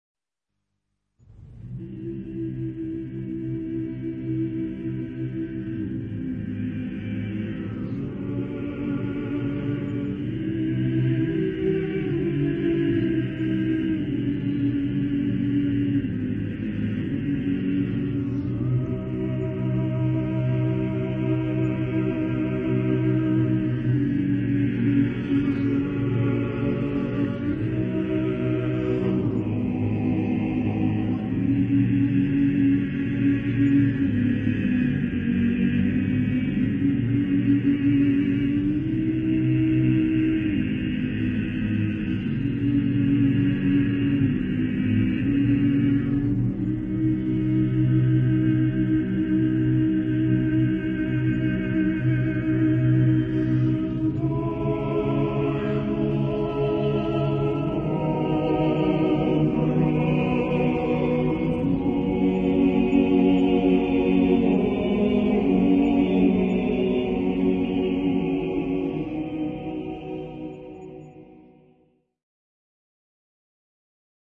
SSAATTBB (8 voix mixtes) ; Partition de choeur et réduction piano pour répétition.
Hymne liturgique (orthodoxe).
Genre-Style-Forme : Sacré ; Hymne liturgique (orthodoxe) ; Orthodoxe Caractère de la pièce : solennel ; pieux
Tonalité : do majeur